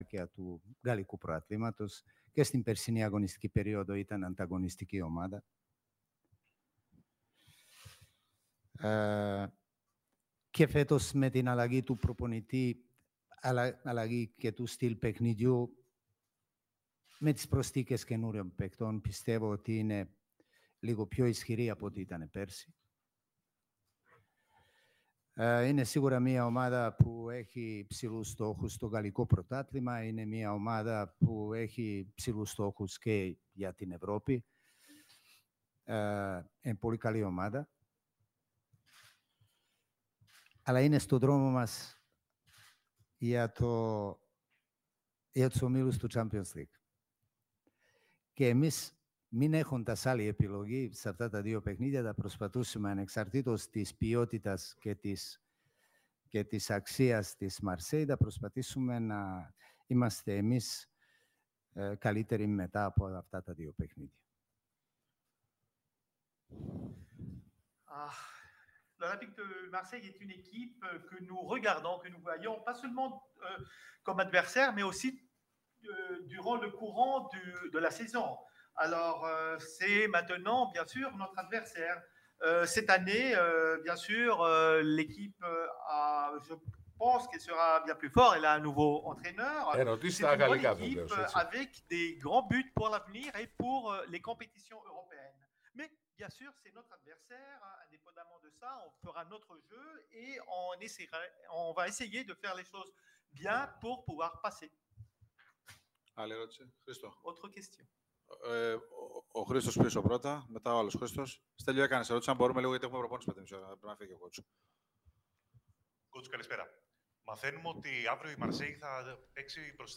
Ο προπονητής του Παναθηναϊκού αναφέρθηκε στα σοβαρά επεισόδια που είχαν ως αποτέλεσμα το θάνατο ενός φίλου της ΑΕΚ, σε δήλωση που έκανε κατά τη διάρκεια της συνέντευξης Τύπου του αγώνα με τη Μαρσέιγ.
Ακούστε ηχητικό απόσπασμα από τις δηλώσεις του Ιβάν Γιοβάνοβιτς: